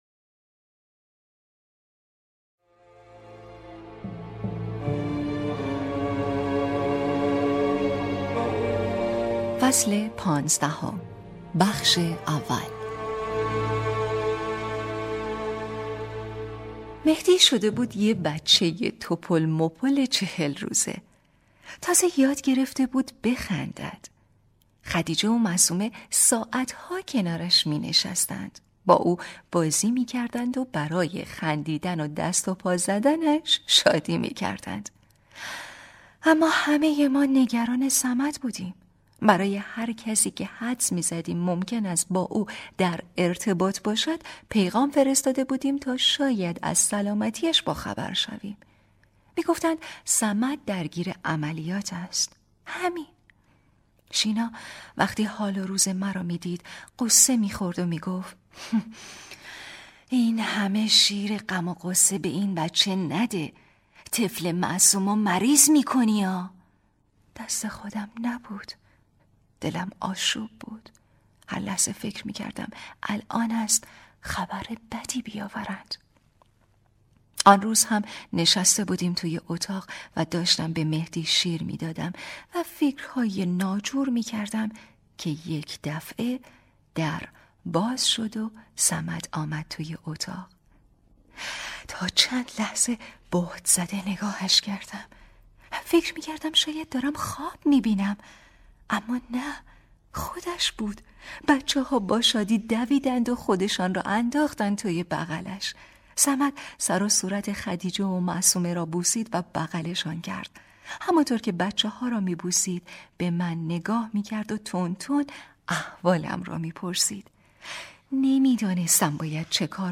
کتاب صوتی | دختر شینا (13)